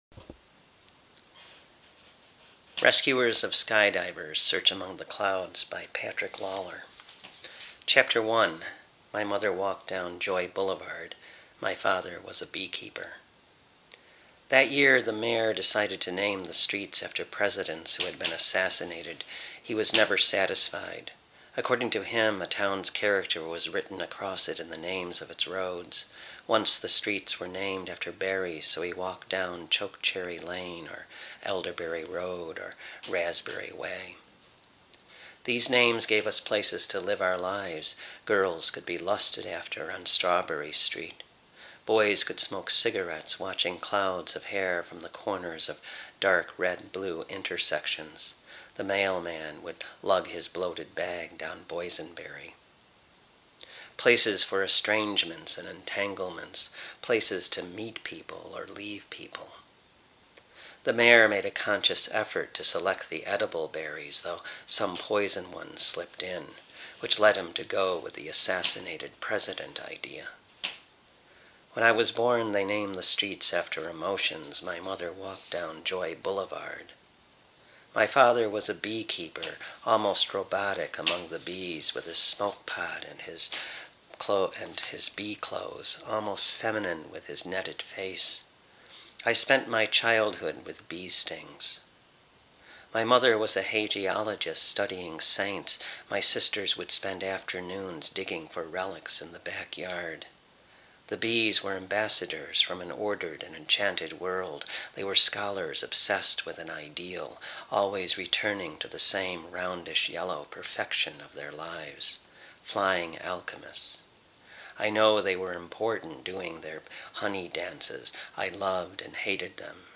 audio | fiction